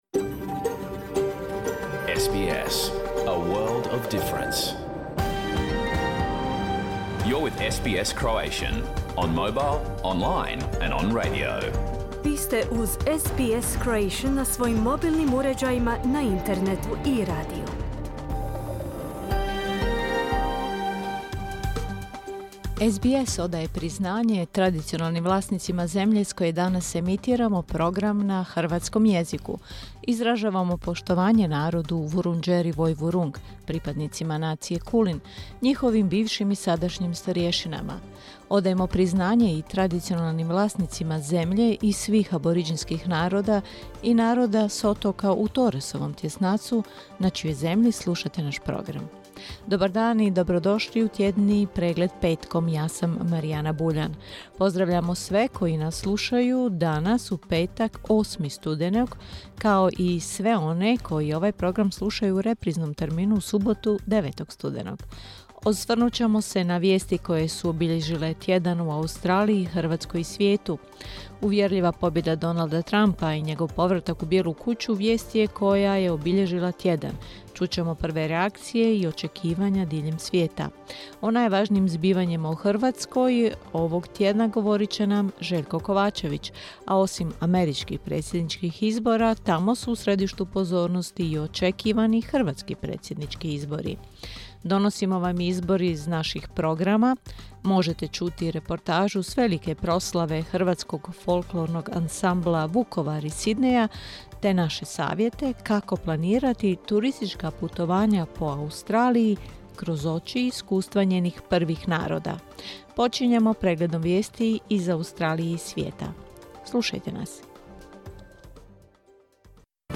Pregled vijesti koje su obilježile tjedan u Australiji, Hrvatskoj i ostatku svijeta. Emitirano uživo na radiju SBS1, u petak, 8. studenog u terminu 11 do 12 sati, po istočnoaustralskom vremenu.